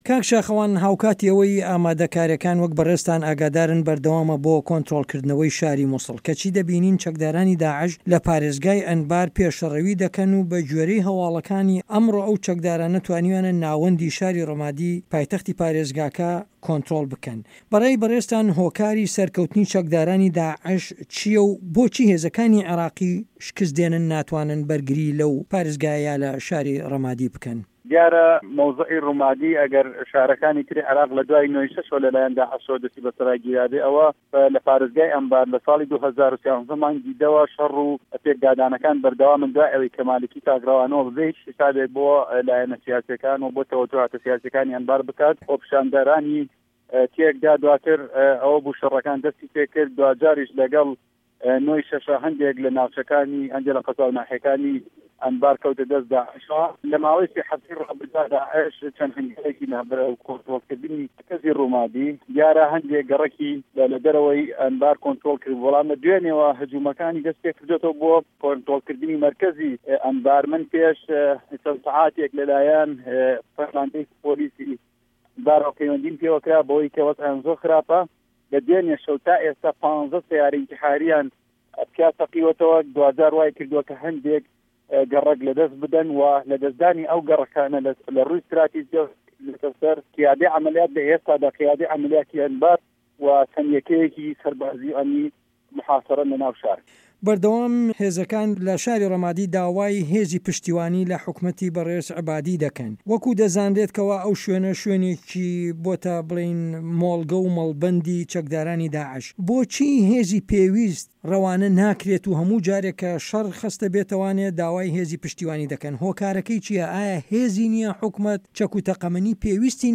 وتووێژ لگه‌ڵ شاخه‌وان عه‌بدوڵا